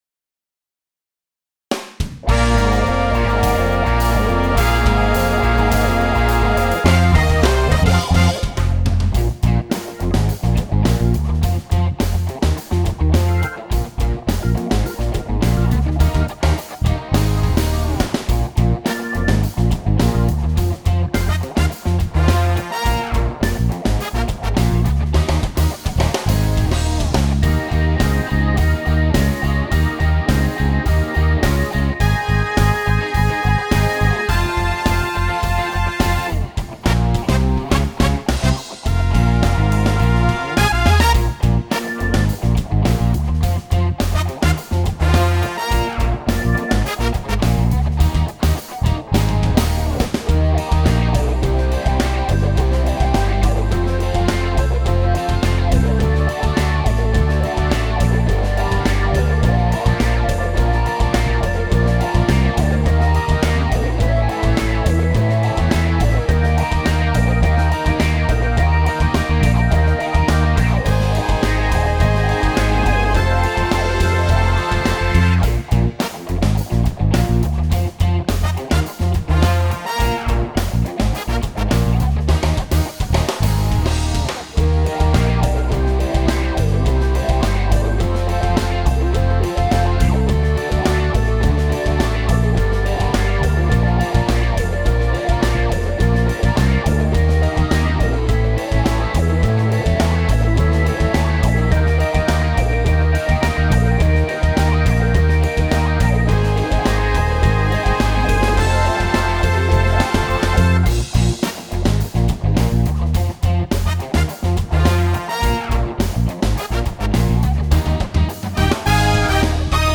Il singolo (bonus track) dell'album live del tour 2008